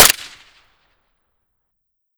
gamedata / sounds / weapons / vintorez_m1 / Bshoot.ogg